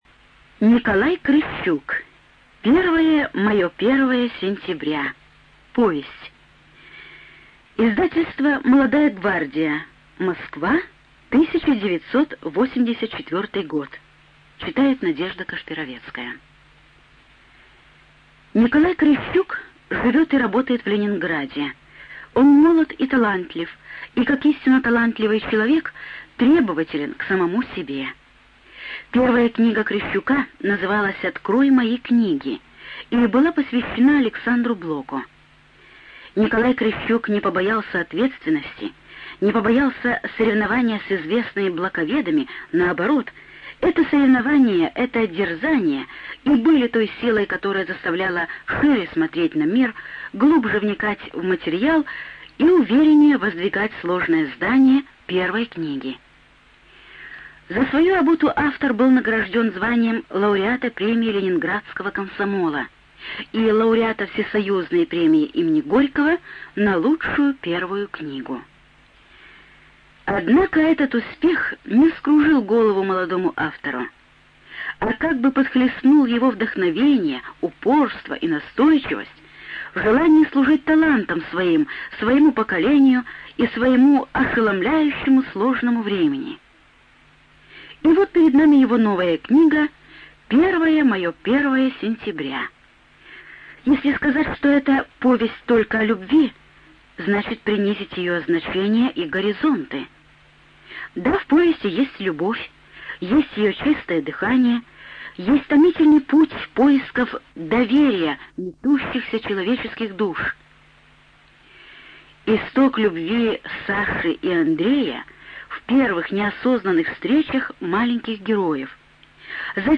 ЖанрСоветская проза
Студия звукозаписиРеспубликанский дом звукозаписи и печати УТОС